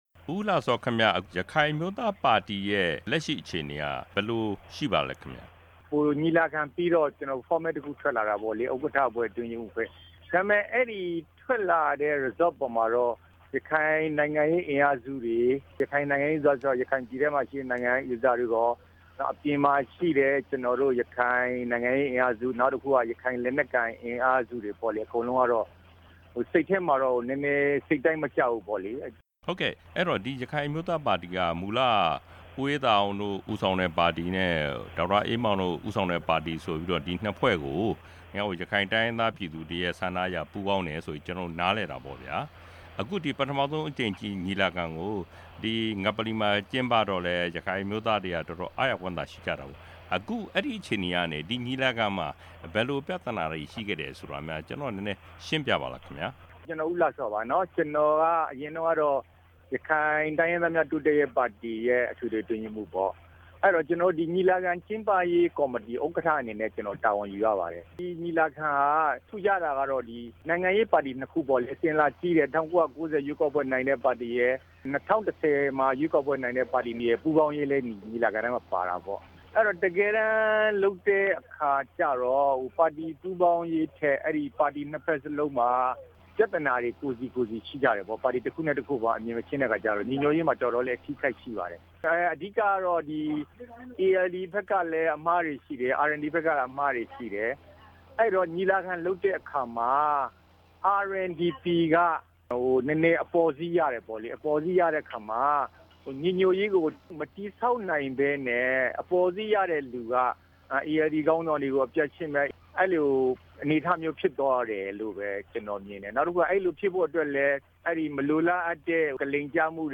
ပါတီညီလာခံအပြီး ရခိုင်အမျိုးသားပါတီရဲ့ နောက်ဆုံးအခြေနေ မေးမြန်းချက်